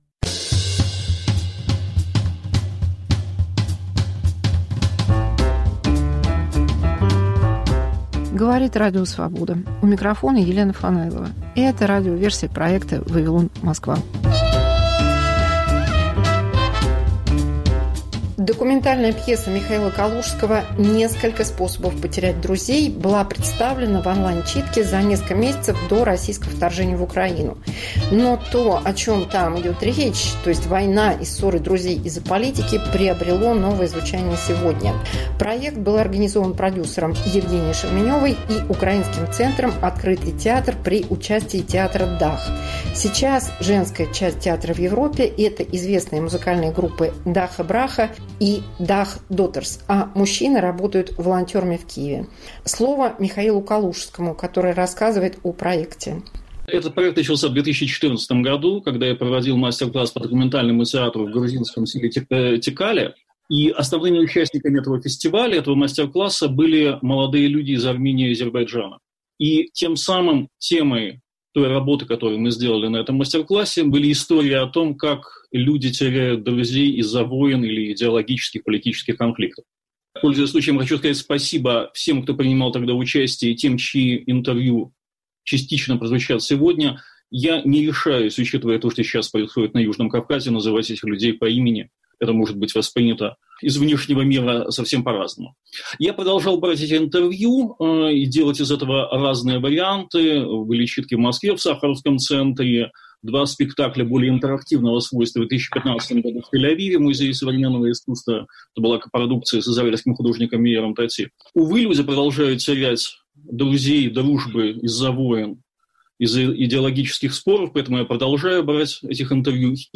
Пьеса Михаила Калужского. Разрыв связей людей.